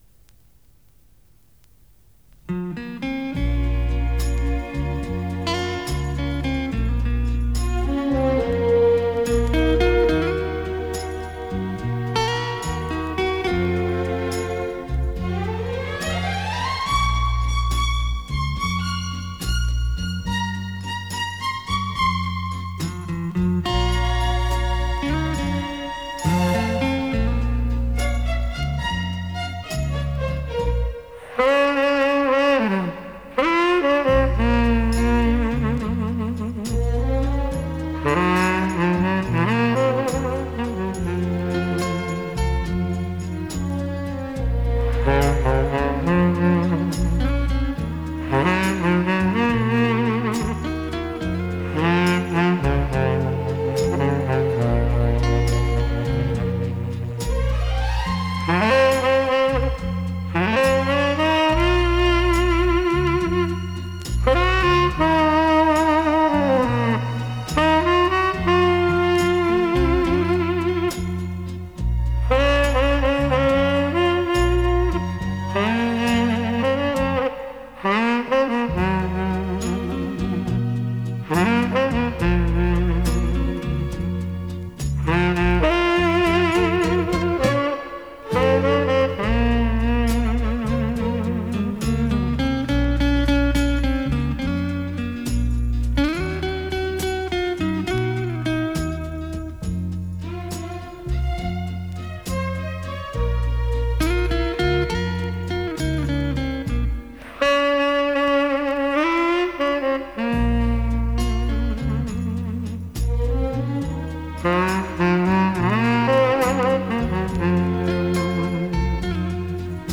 Japan sax - с винила 70-х